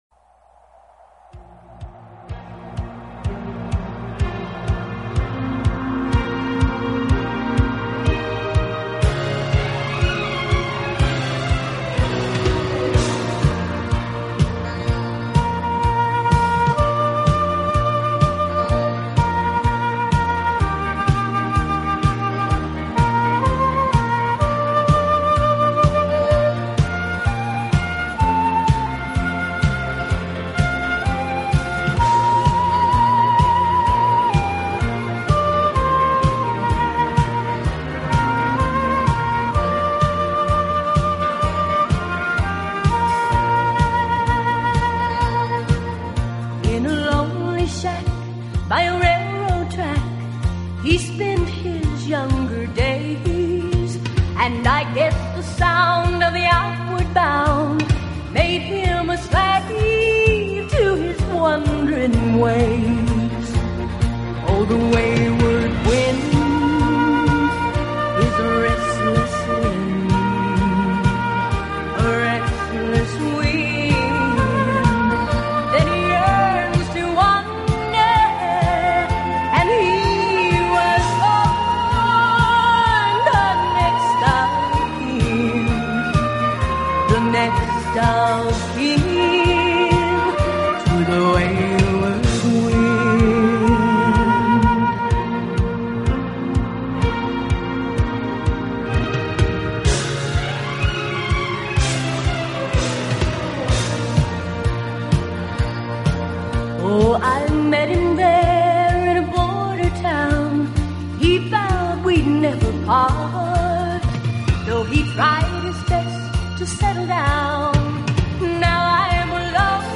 【长笛】
【新世纪长笛】
Genre................: Instrumental Pop